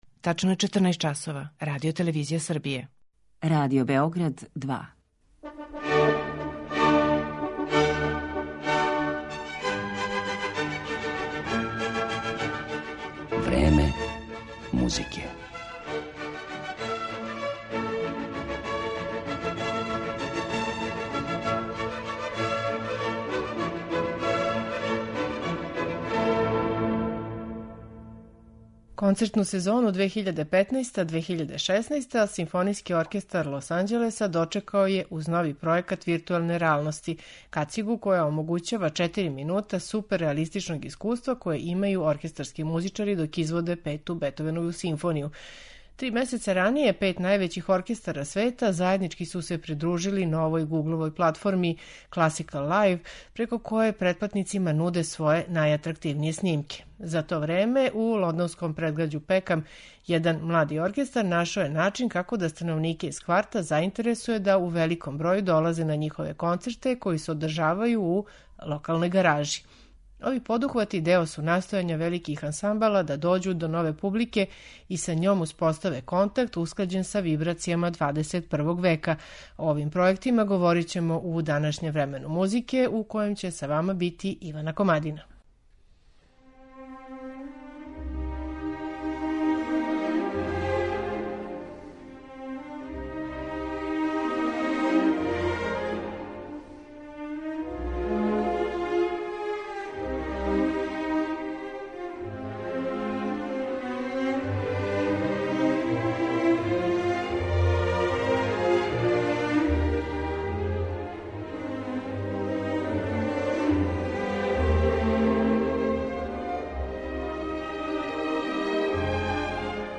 О овим пројектима, којима велики ансамбли настоје да дођу до нове публике, говорићемо данашњем Времену музике , уз најновије снимке Берлинске филхармоније, Лондонског симфонијског оркестра, симфонијских оркестара из Детроита и Лос Анђелеса, оркестра Консертхебау и Њујоршке филхармоније.